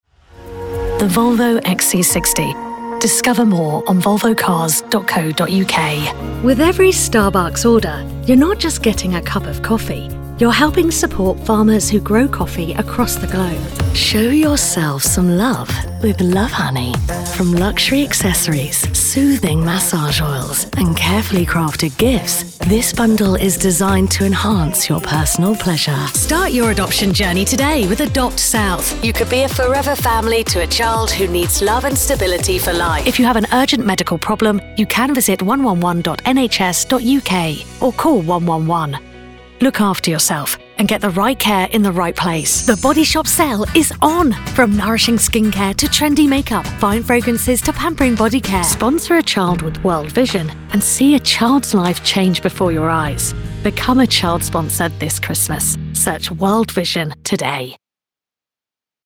Natural, Accesible, Versátil, Maduro, Suave
Comercial
Para describir rápidamente su voz: es cálida, natural, animada, fresca, atrevida y comercial.